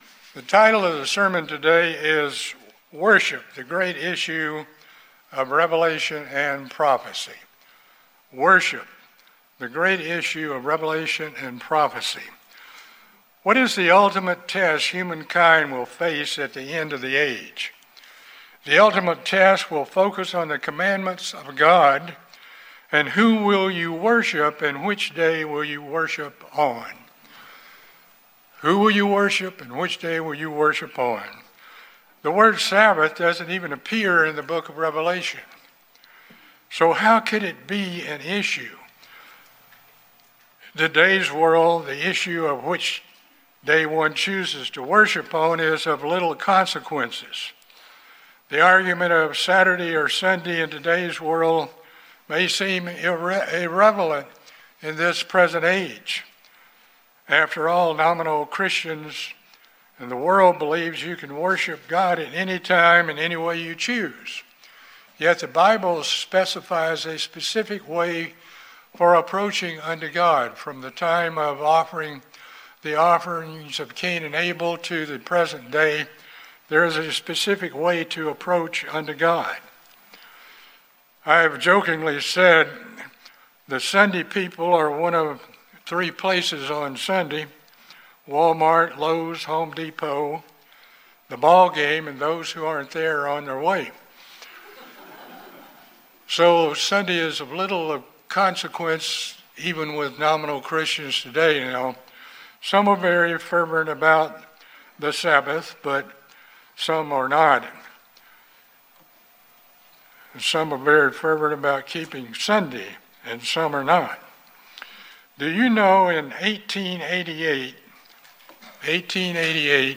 This sermon shows the relationship of the Sabbath and God's creation.